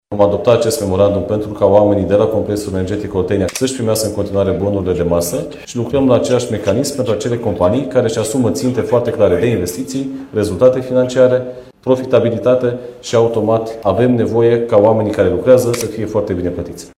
Ministrul Energiei, Bogdan Ivan, îi asigură pe mineri că își vor primi bonurile de masă.